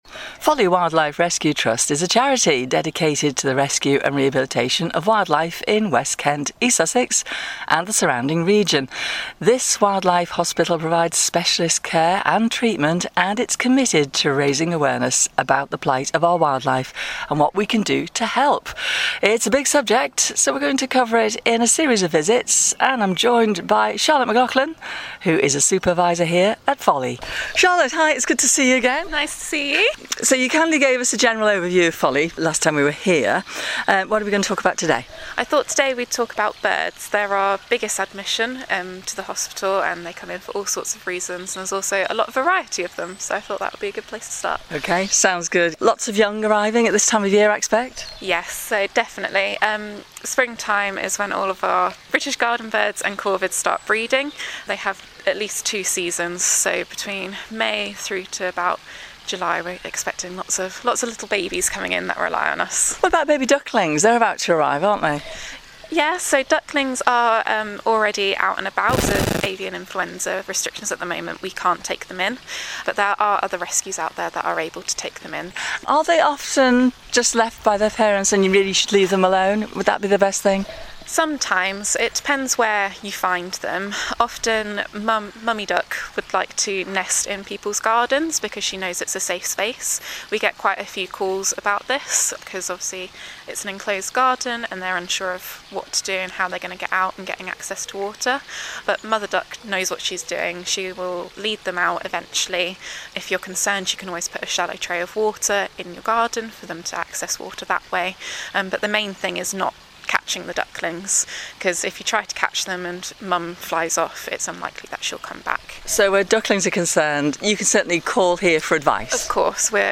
For more information about Folly Wildlife Rescue: Home - Folly Wildlife Rescue You can listen to the interview here: Listen to this audio